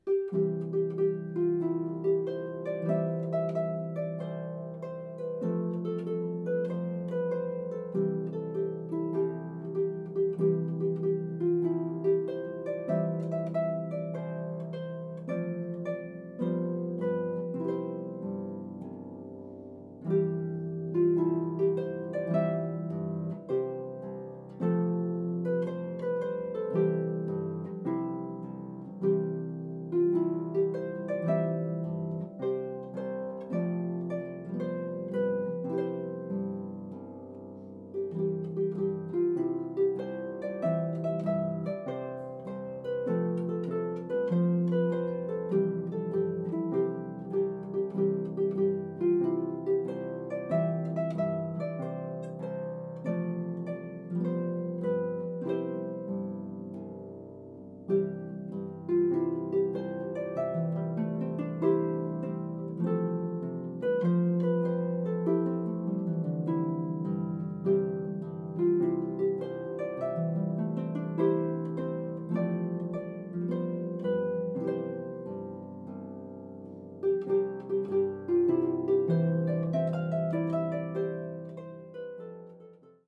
solo pedal harp. This upbeat American folk song